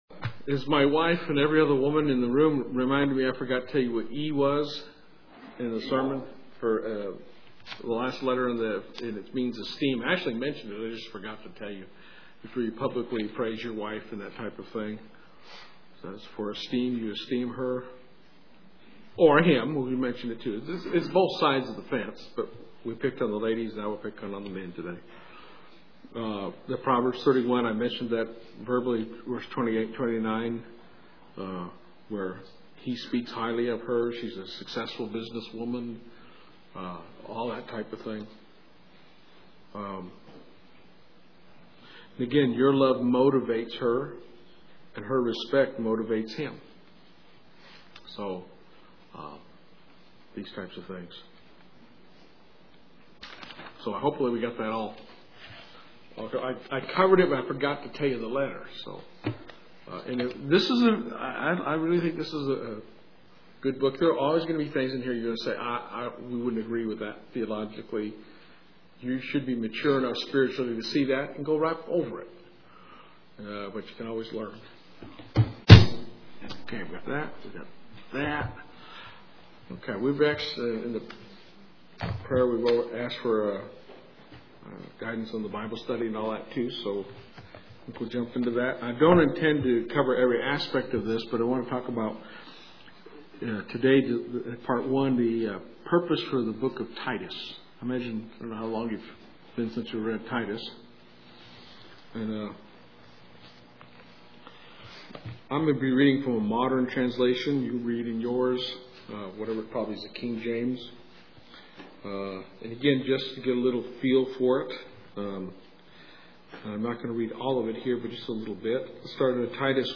Listen to a Bible Study on what we can learn about and from the book of Titus.